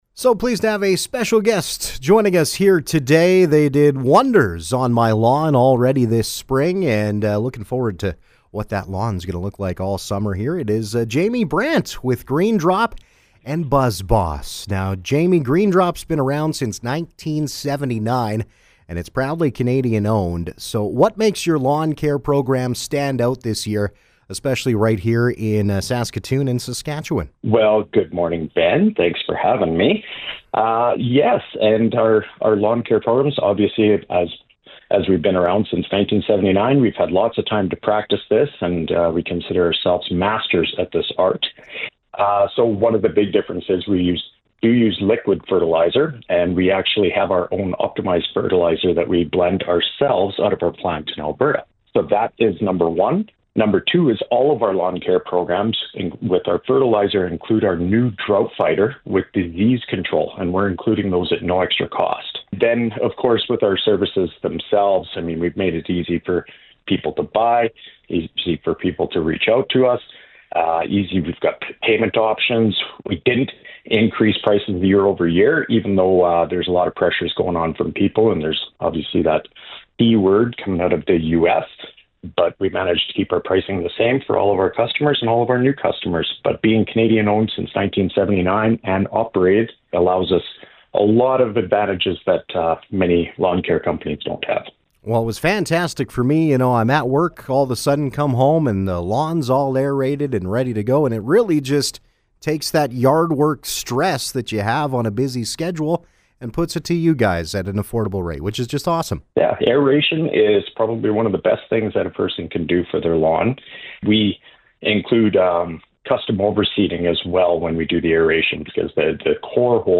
Interview: Green Drop & Buzz Boss
green-drop-interview.mp3